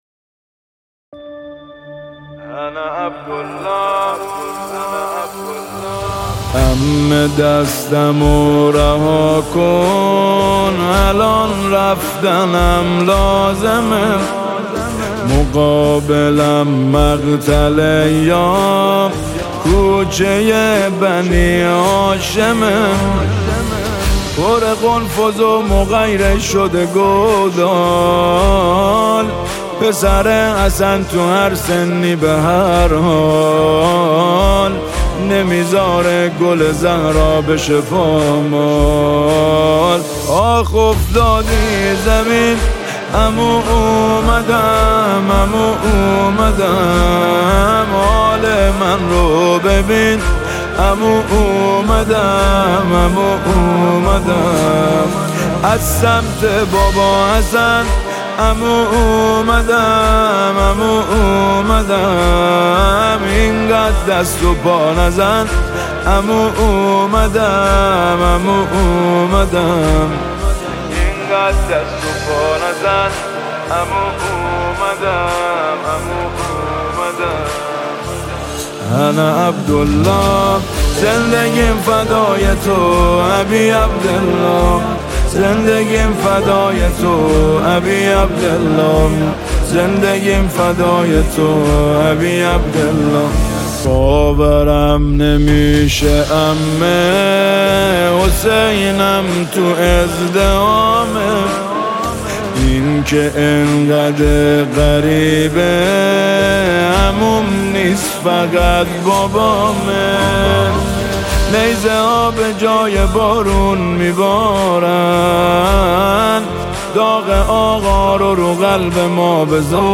نماهنگ ماه محرم
مداحی شب پنجم ماه محرم